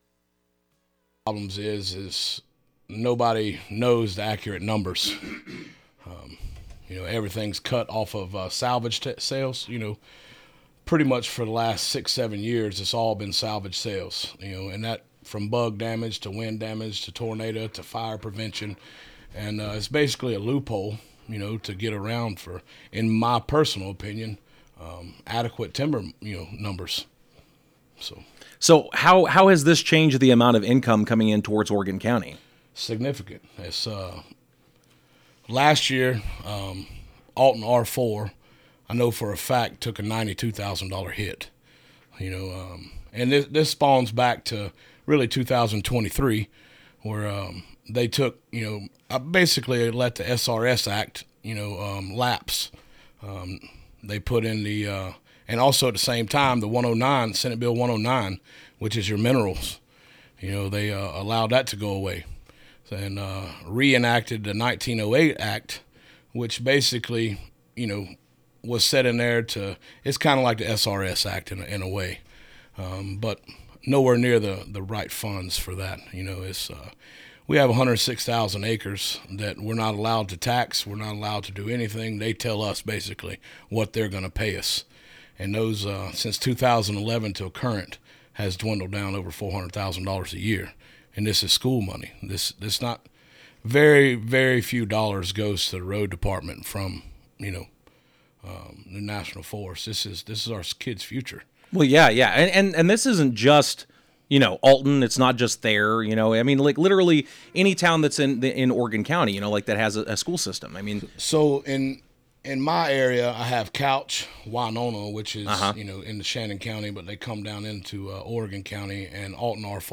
Oregon County Commissioner Jake Parker in the studio of KUKU on February 2nd, 2026.